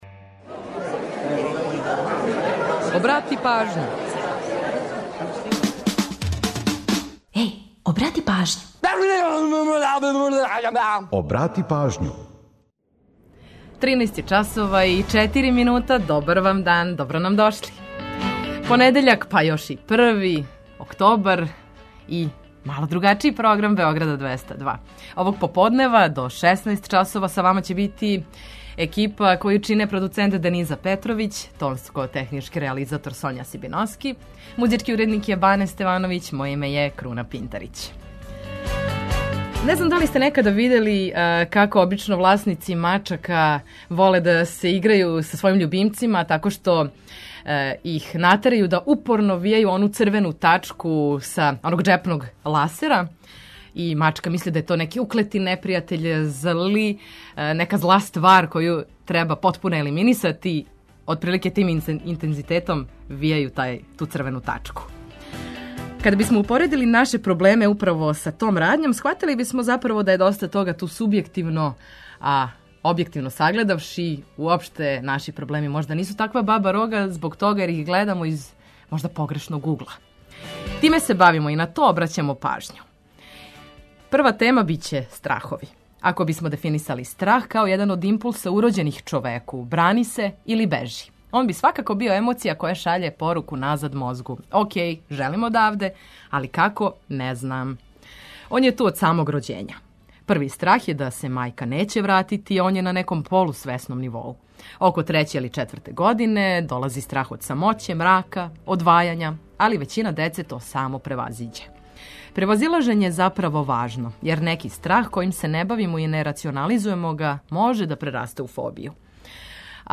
Корисне информације и омиљена музика су обавезни детаљ.